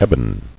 [eb·on]